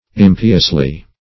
-- Im"pi*ous*ly, adv.